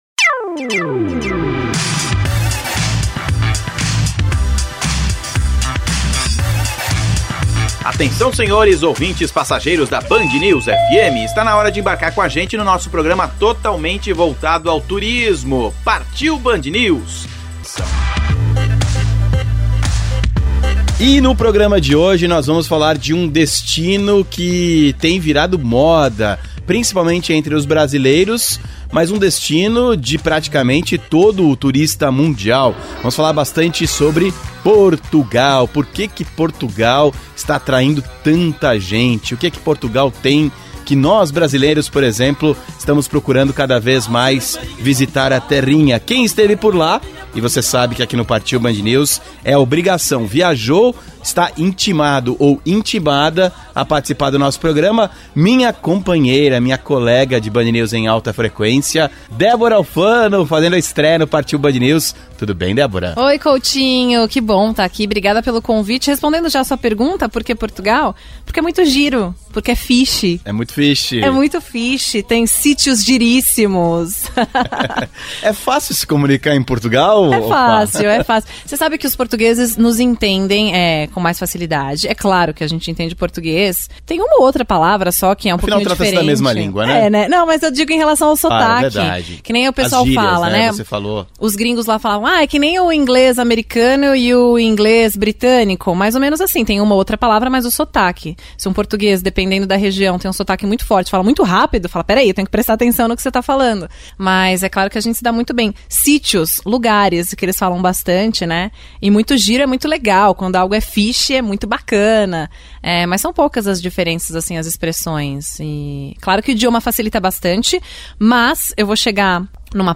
Cultuga em entrevista a Rádio Band News FM